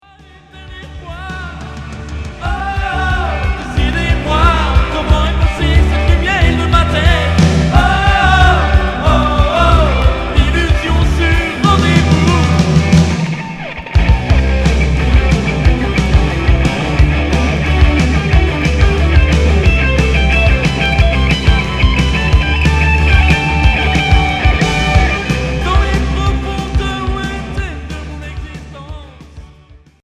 Hard FM